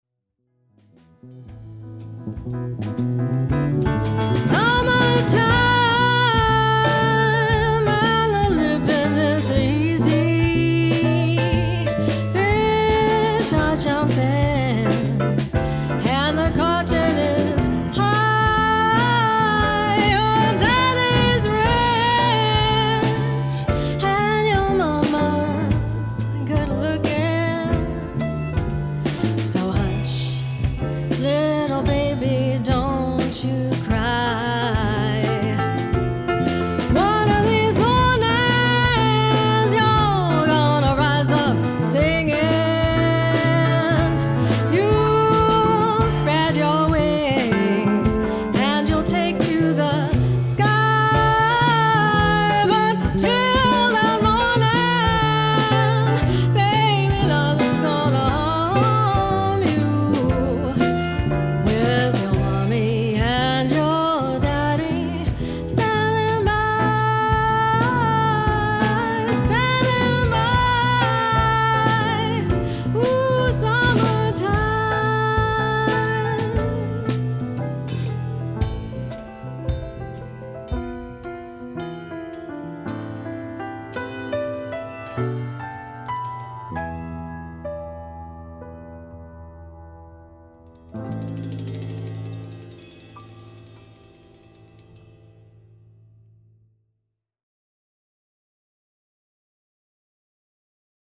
∗ Recorded live at Audio Lair Studio;the rest are sample clips from live performances